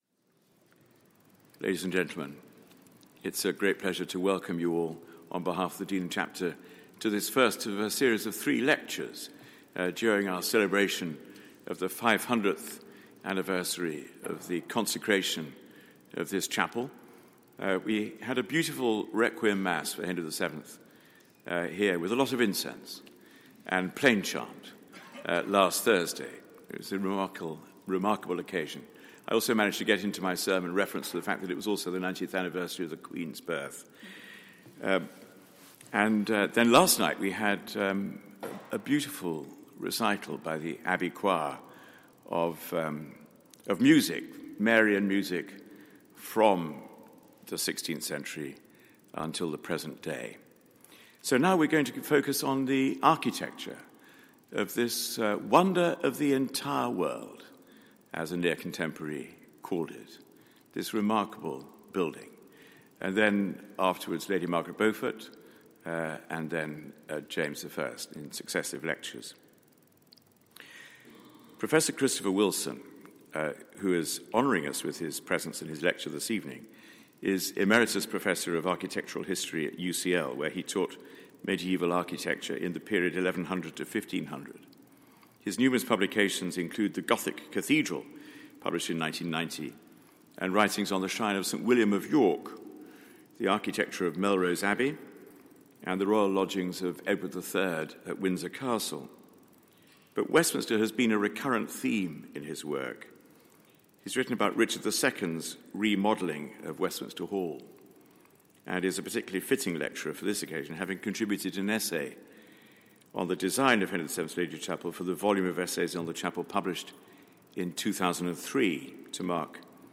500 Years of Wonder - Lecture